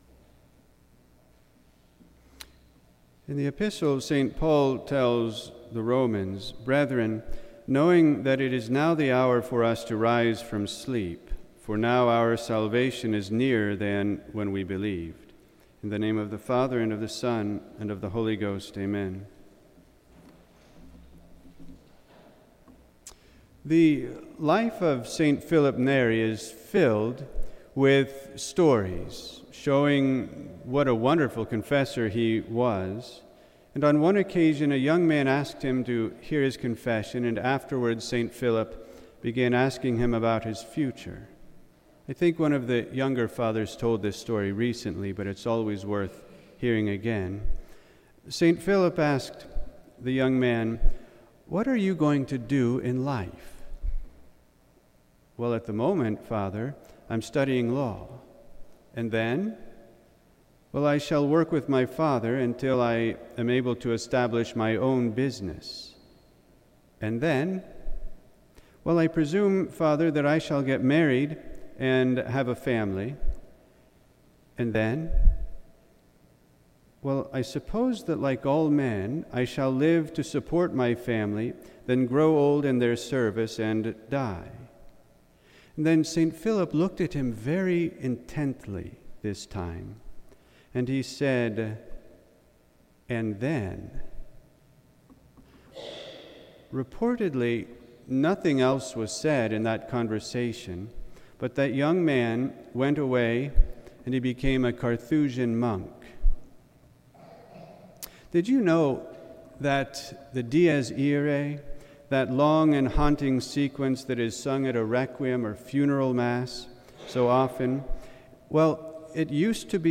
This entry was posted on Sunday, November 30th, 2025 at 4:24 pm and is filed under Sermons.